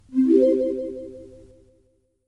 Play, download and share sucessfollowalert original sound button!!!!
soft-success.mp3